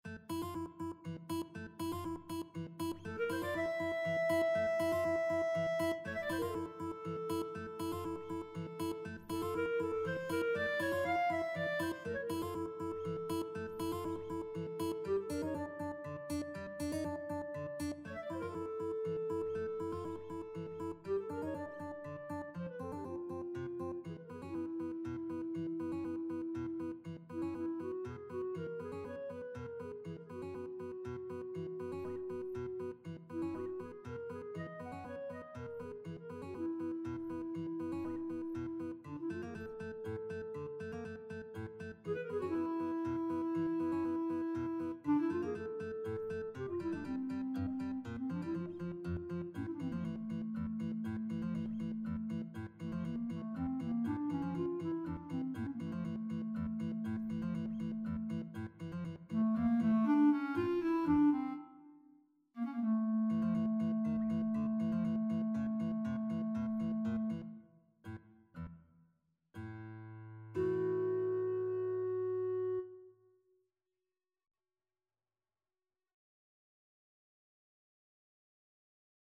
3/4 (View more 3/4 Music)
Allegro spagnuolo (View more music marked Allegro)
Classical (View more Classical Guitar-Clarinet Duet Music)